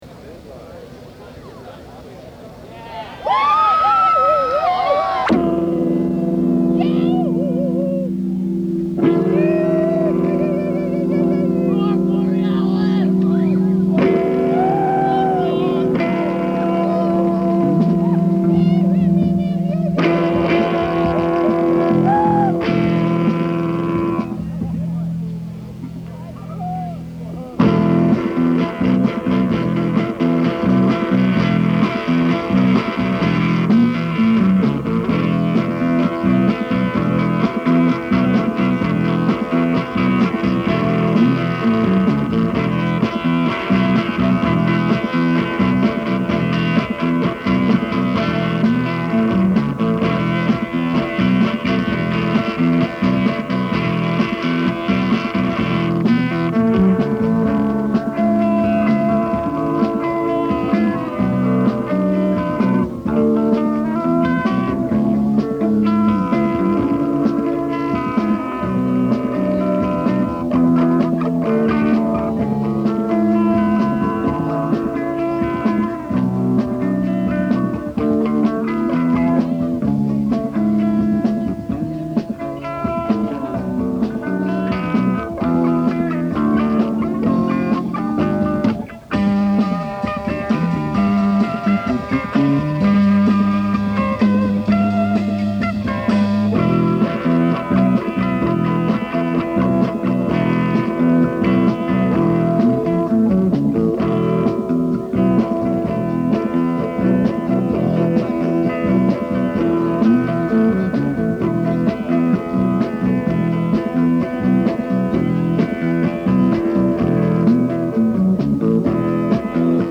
(100 miles east of Victorville, CA)